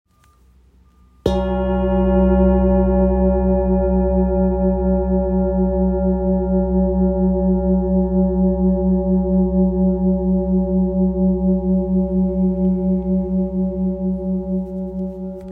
This enhanced oscillation makes it perfect for bodywork, as its deep, resonant tones penetrate the body, promoting relaxation, balancing energy centers, and restoring harmony on a cellular level.
Its rich tones and sustained vibrations make it a versatile tool for meditation, energy healing, and therapeutic use.